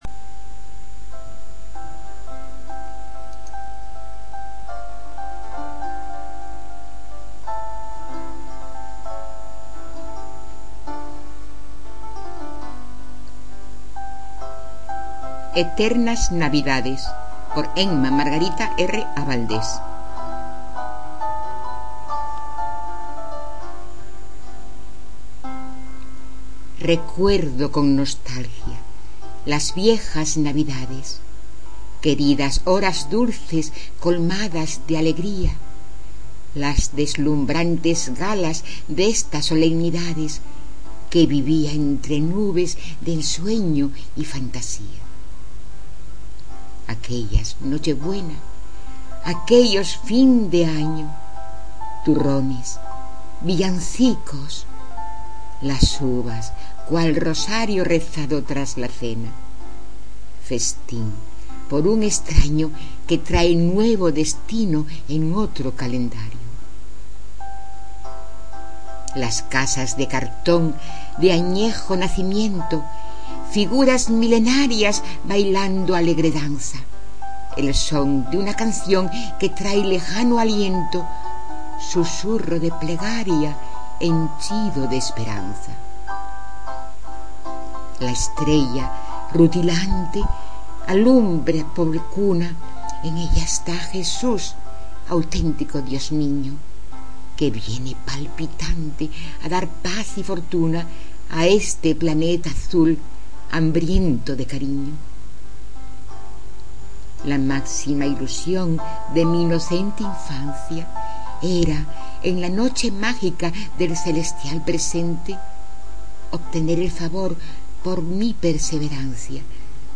En mp3, recitada por la autora.